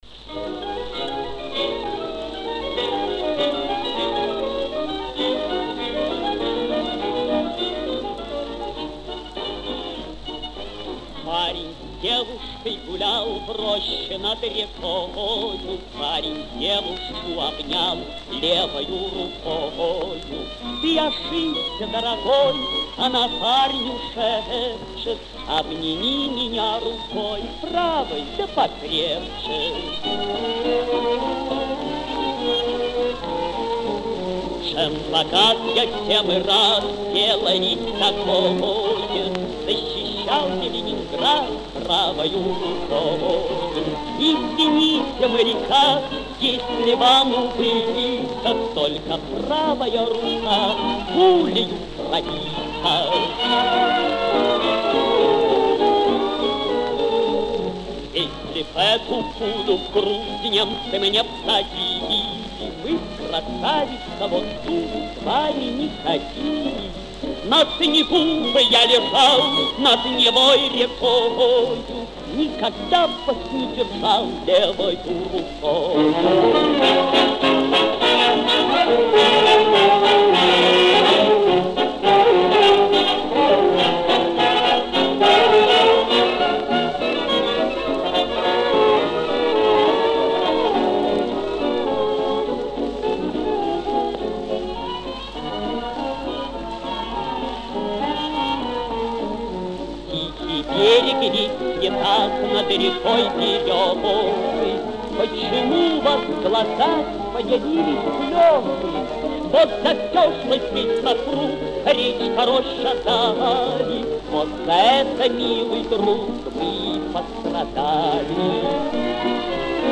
Повышение качества - вариант без реставрации.